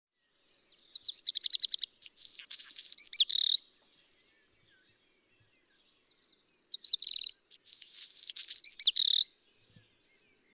le Rougequeue noir
Il chante souvent du sommet d'un toit ou d'une antenne. Le bruit de papier froissé est caractéristique.
Rougequeue_noir_MN1.mp3